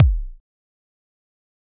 EDM Kick 41.wav